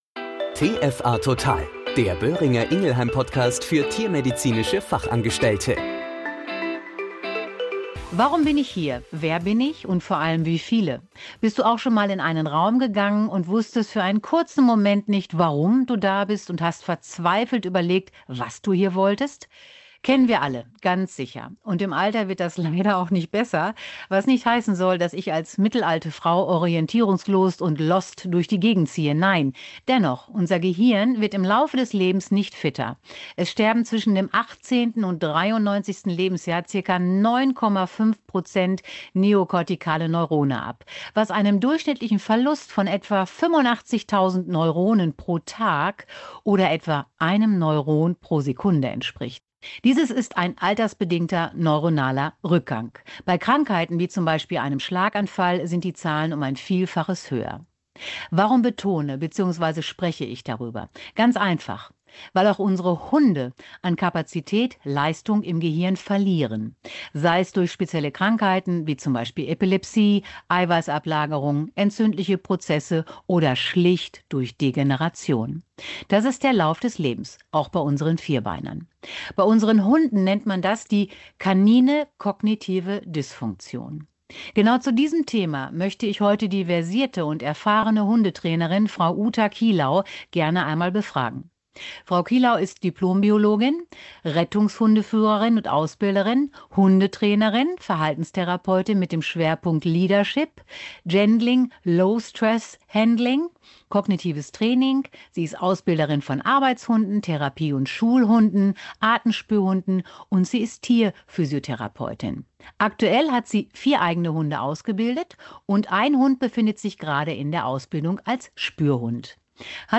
In diesem Interview klärt eine Expertin auf dem Gebiet der Verhaltenslehre zu dieser Thematik auf!